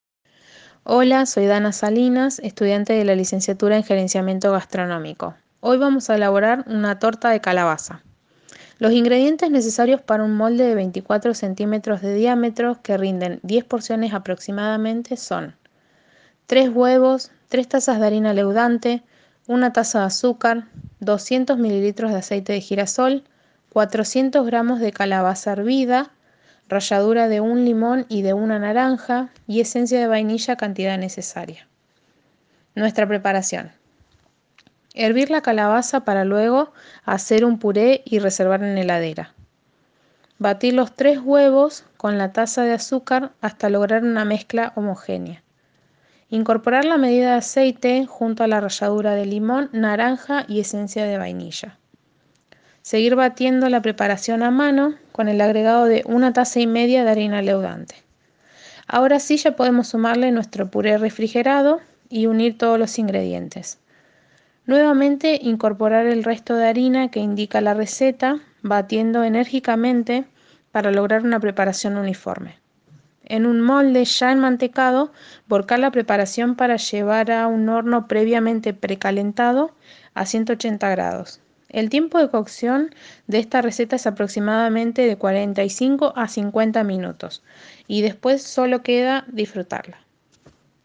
Audio receta de la torta de calabaza